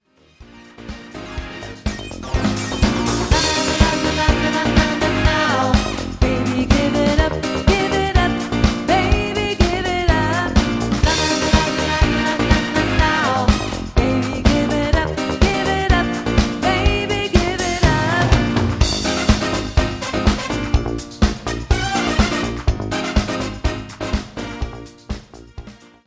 junge dynamische Band mit Sängerin für Hochzeiten
• Cover 1